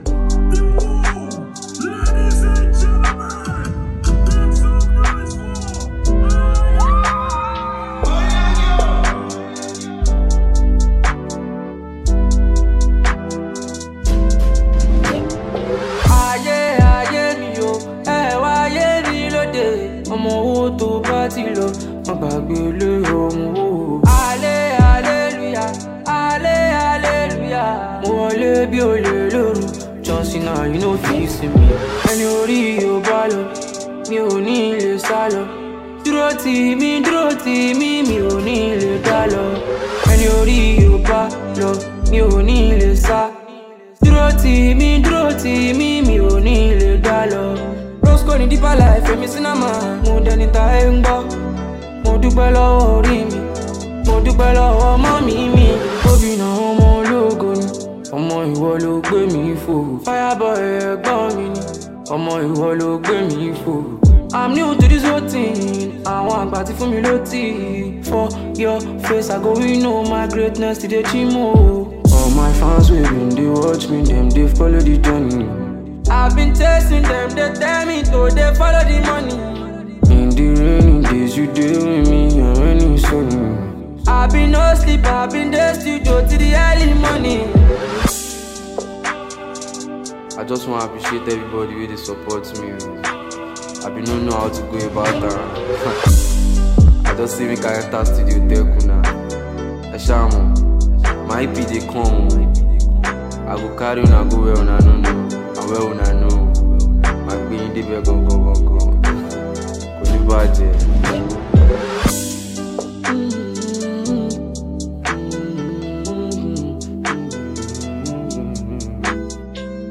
Talented Nigerian Singer